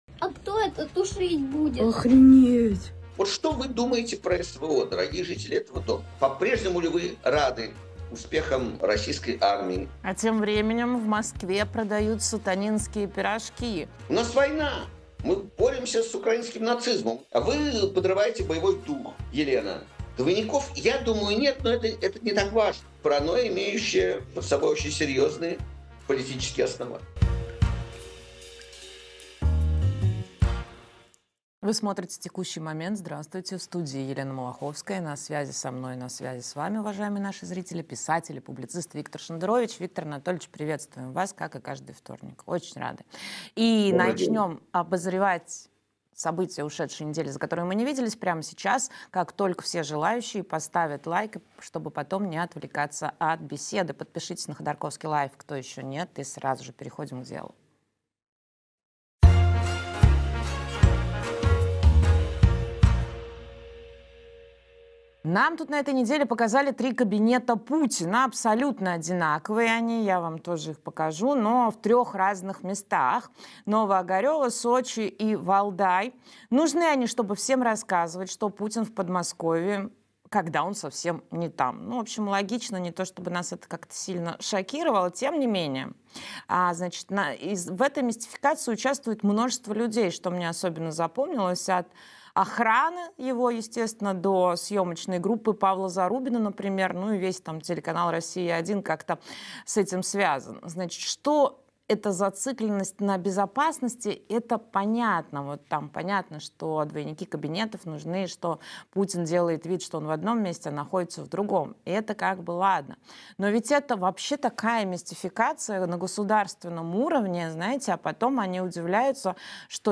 Виктор Шендерович писатель